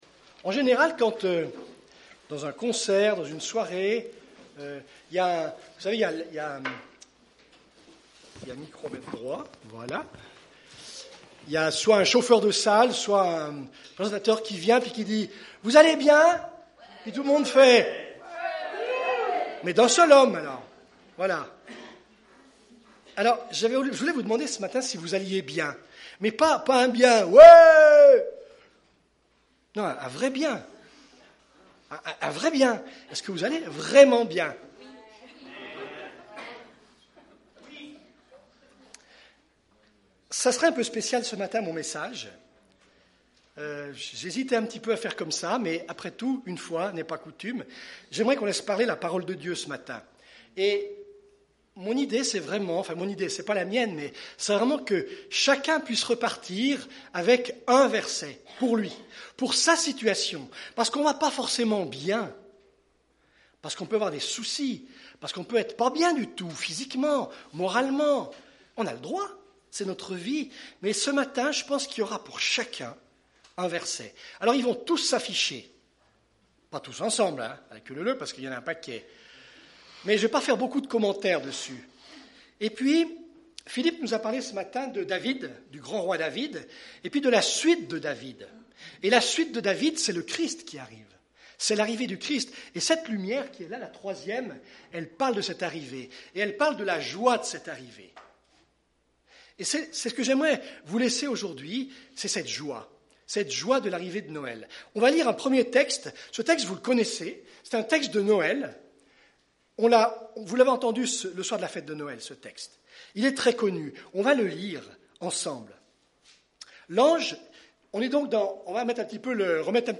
Culte du 16 décembre